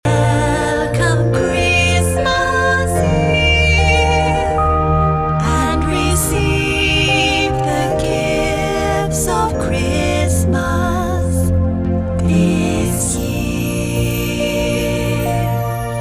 soaring vocals blend in beautiful harmonies